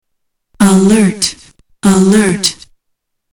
Tags: Science/Nature The Echo soundboard Echo Echolocation Sound Sound